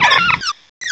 pokeemerald / sound / direct_sound_samples / cries / budew.aif